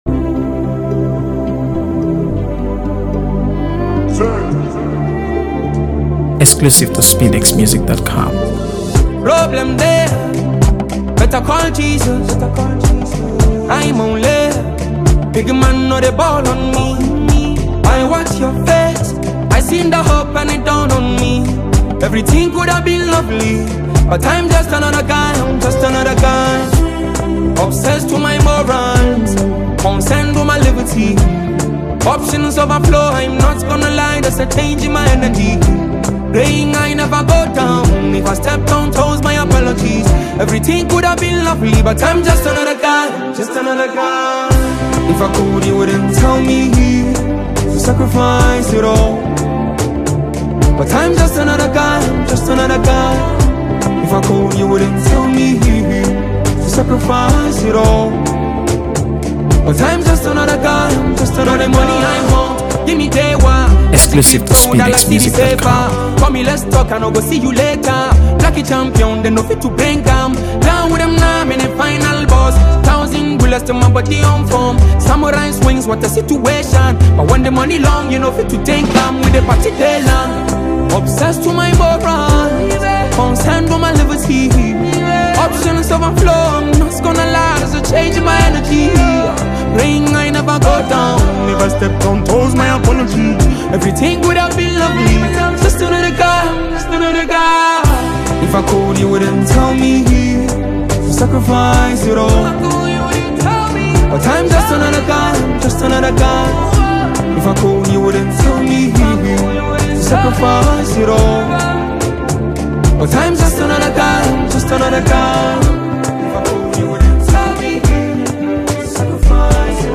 AfroBeats | AfroBeats songs
blends infectious rhythms with deeply heartfelt lyrics